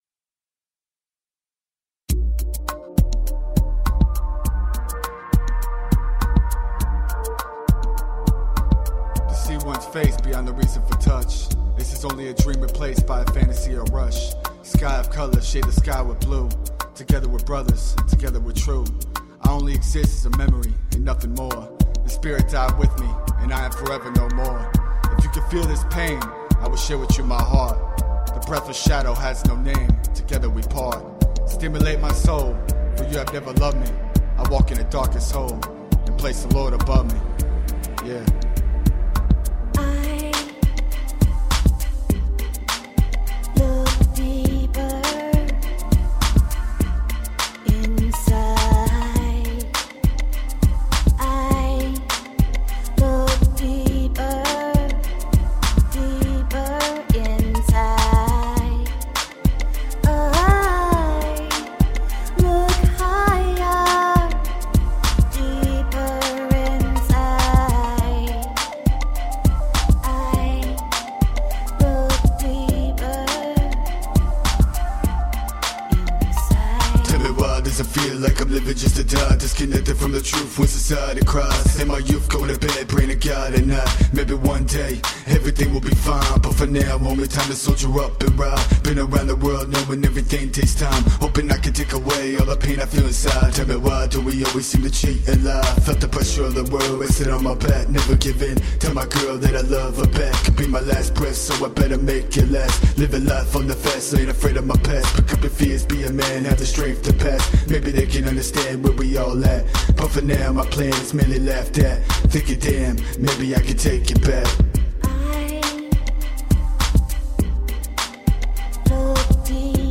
Award winning independent hip hop group.
Tagged as: Hip Hop, Other